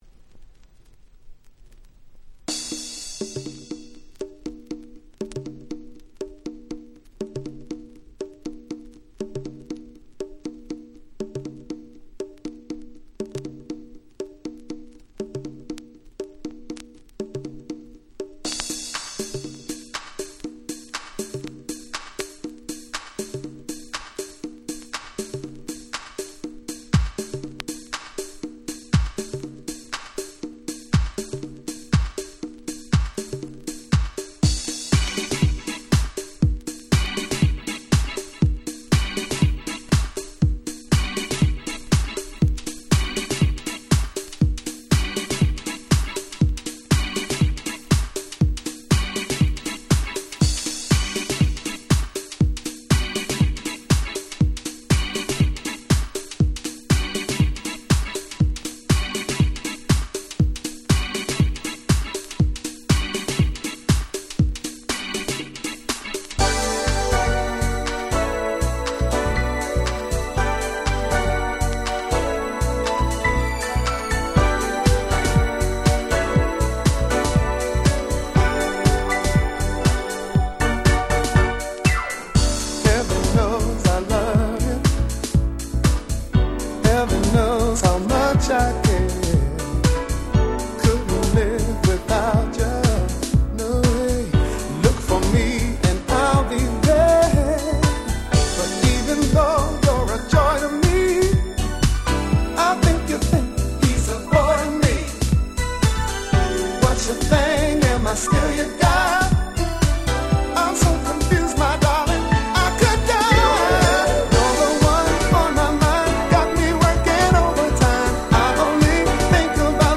93' Super Nice R&B !!
まさに「大人の夜」感満載の最高の1曲！！